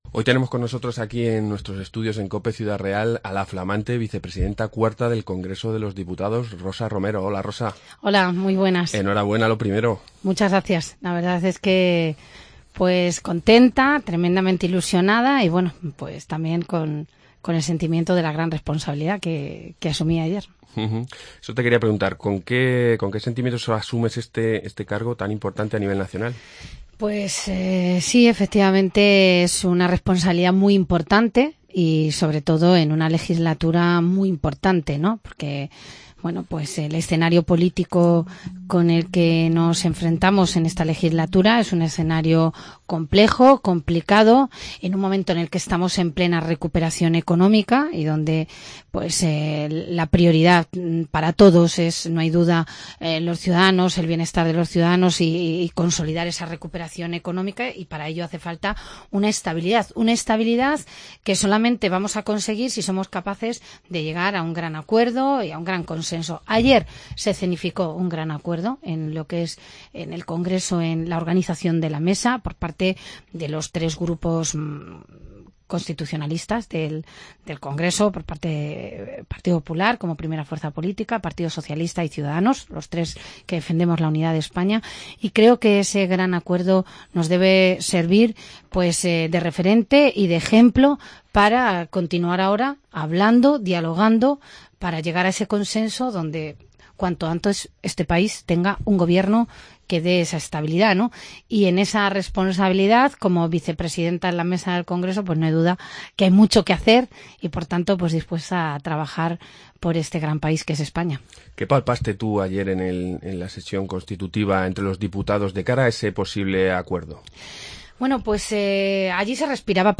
Entrevista Rosa Romero, vicepresidenta cuarta del Congreso 14-1-16